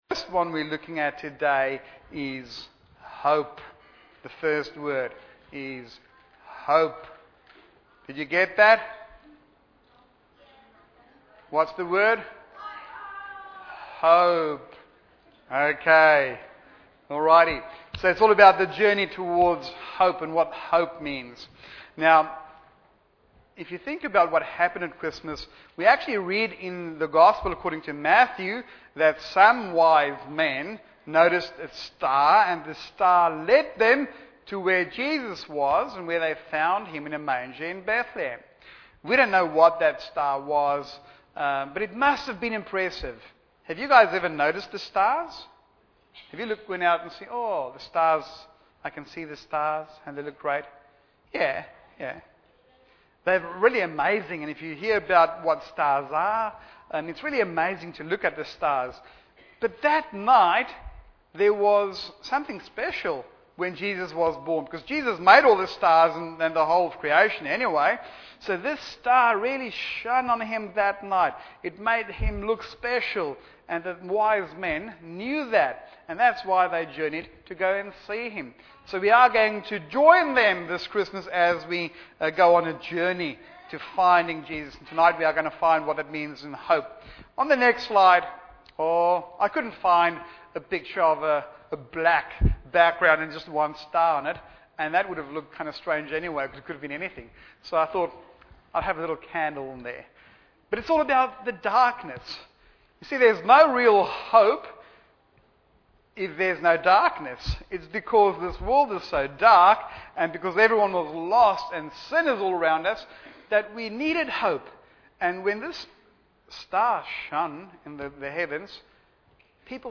Bible Text: Luke 2:8-20 | Preacher